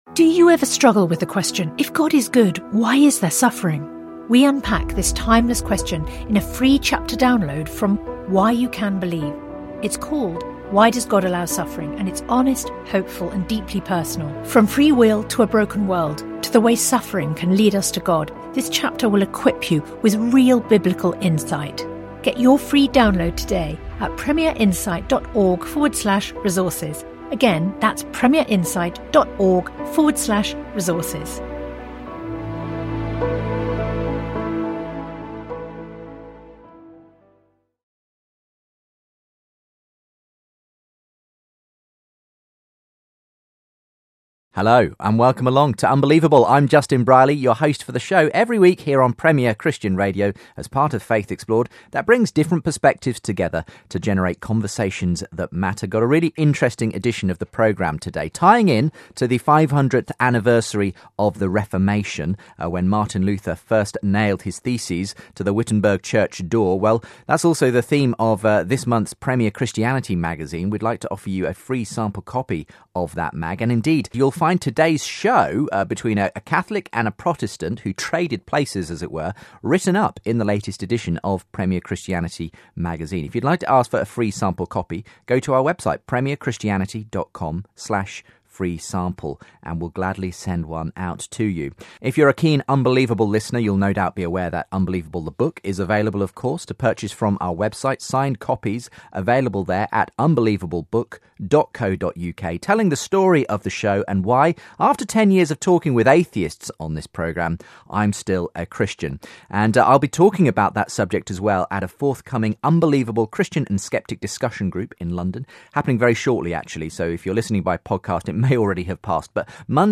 One left his Catholic background for Reformed Baptist faith, the other went from Anglican to staunch Catholic.